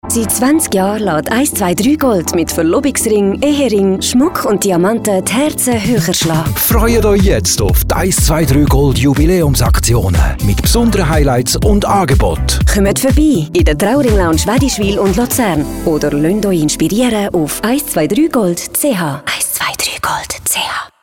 Sprecherin, Synchronsprecherin, Moderatorin, Sängerin